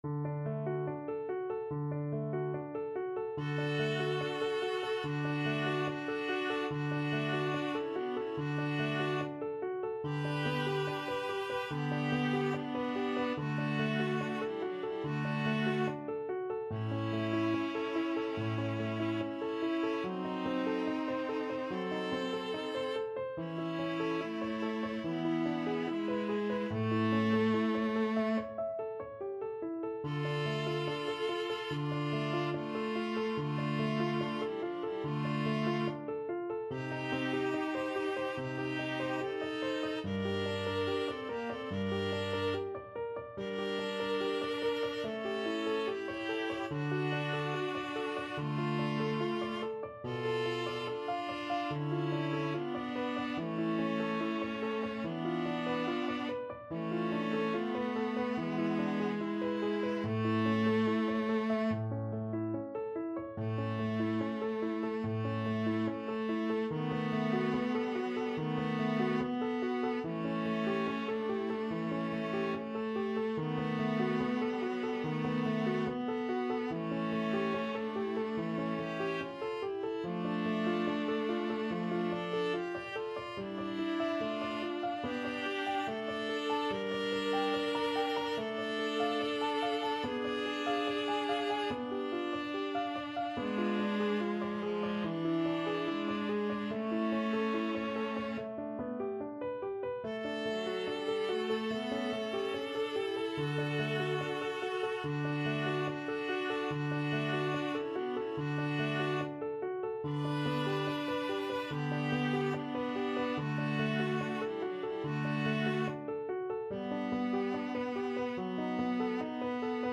Andante =72
Classical (View more Classical Viola Duet Music)